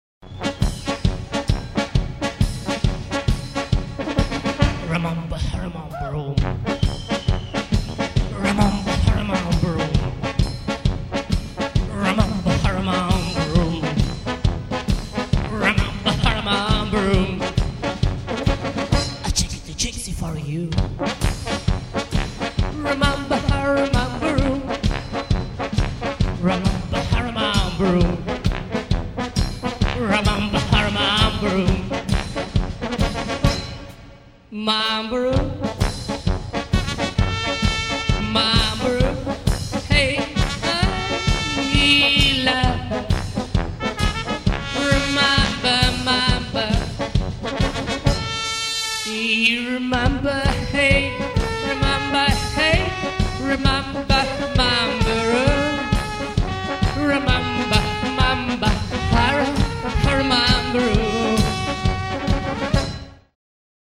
Первый концерт на Шаболовке (1993)
AUDIO, stereo